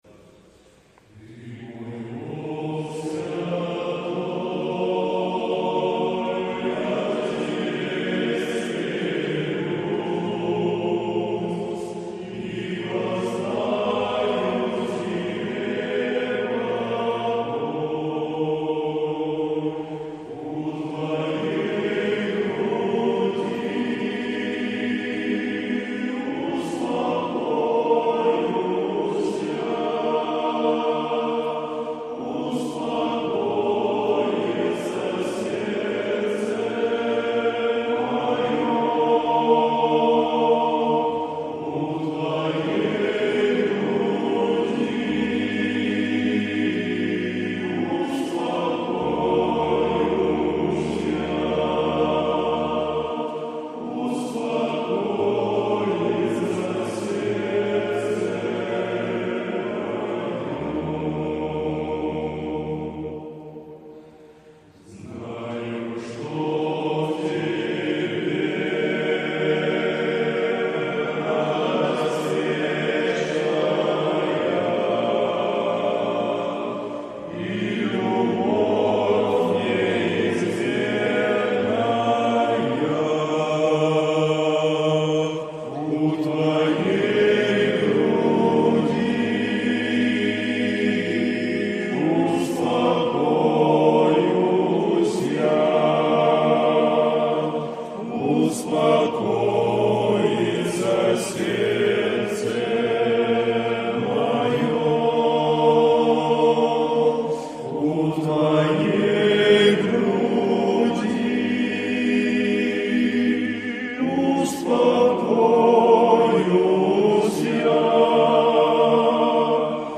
153 просмотра 217 прослушиваний 7 скачиваний BPM: 172